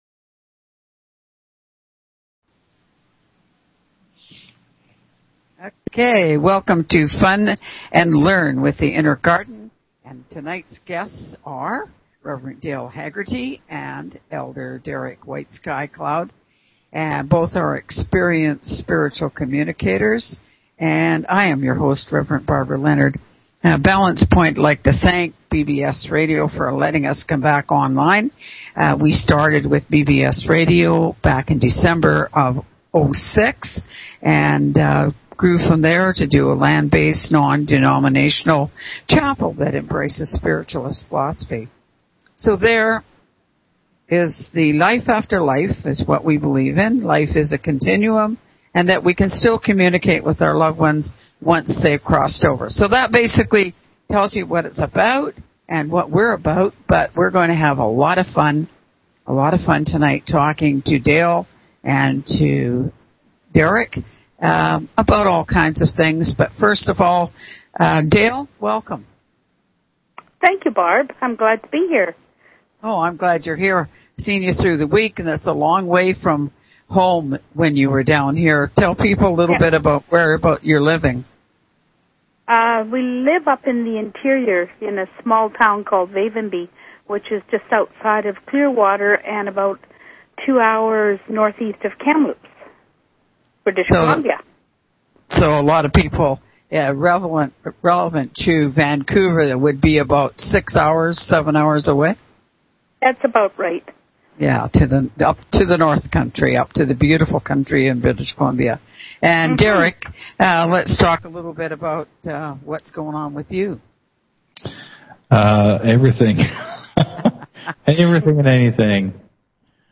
Talk Show Episode, Audio Podcast, Fun_and_Learn_with_Inner_Garden and Courtesy of BBS Radio on , show guests , about , categorized as